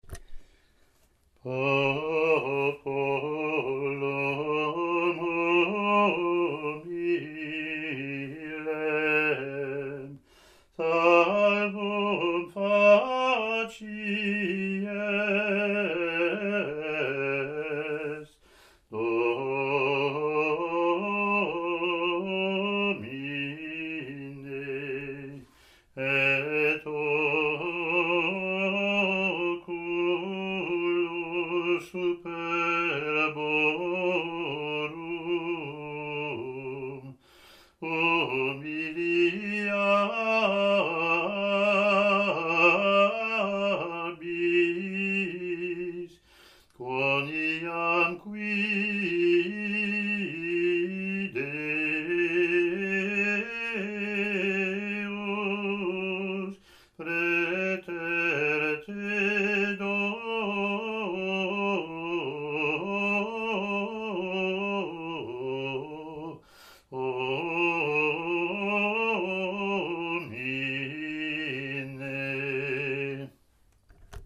Latin antiphon)